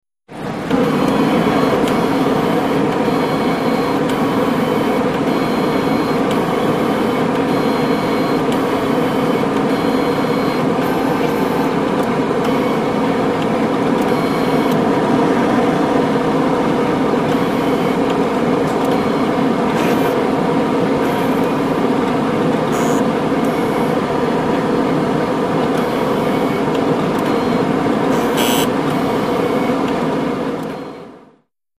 BloodAnalyzerMotor PE266702
Blood Analyzer 2; Blood Analysis; Fan / Motor, Printing, Buzzes; Close Perspective. Hospital, Lab.